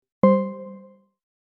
7. Включение режима шумоподавления аирподс
airpods-shumodav.mp3